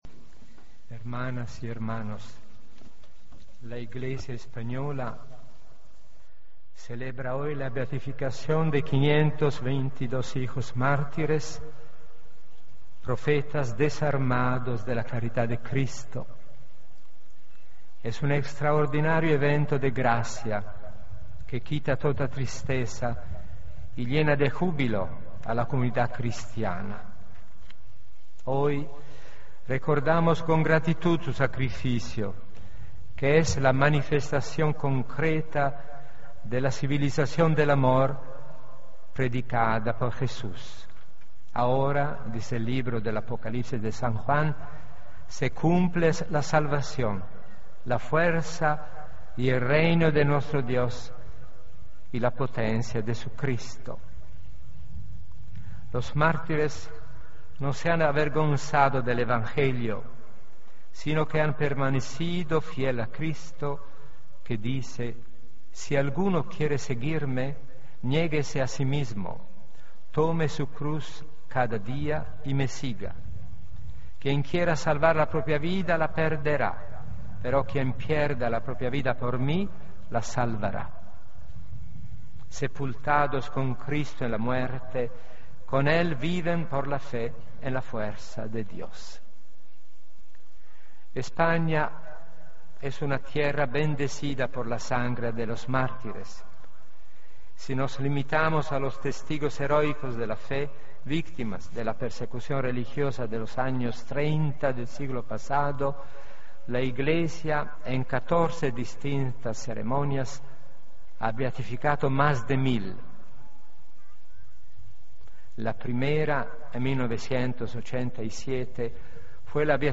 Homilía 13 Octubre de 2013